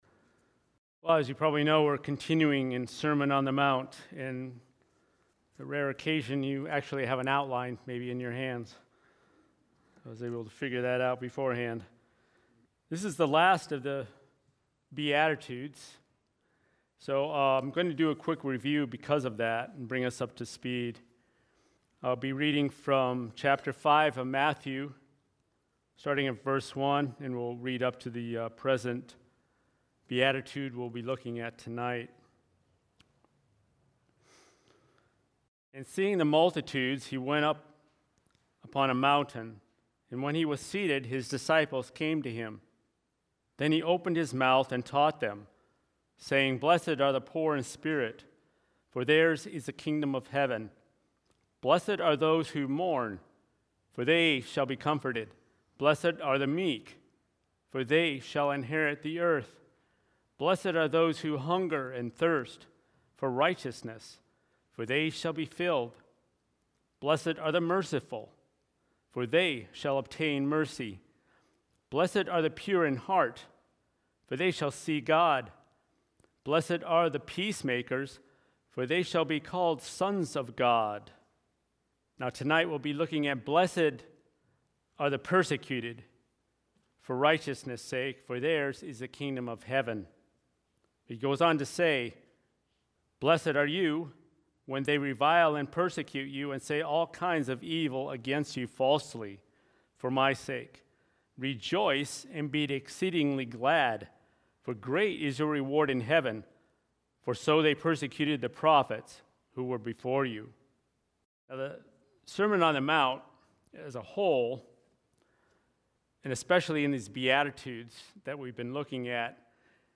Sermon on the Mount: Blessed are the Persecuted | Quidnessett Baptist Church